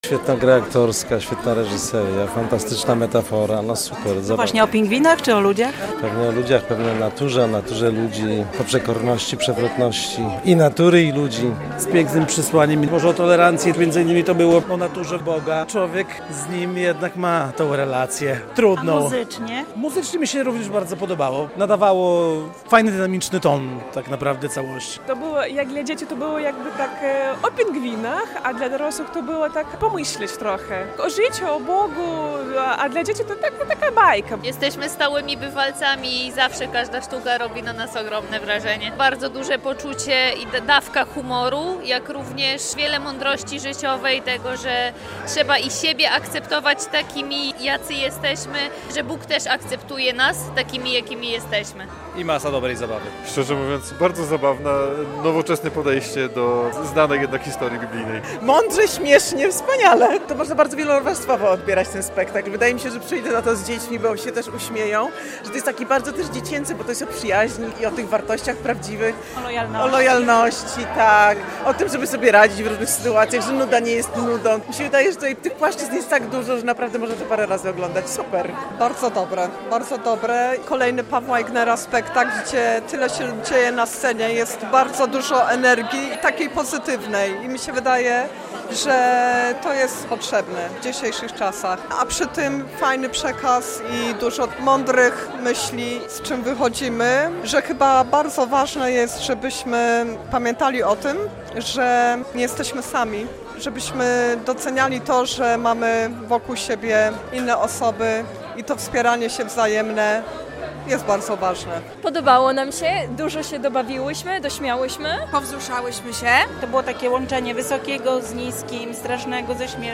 Wrażenia widzów po spektaklu "O ósmej na Arce"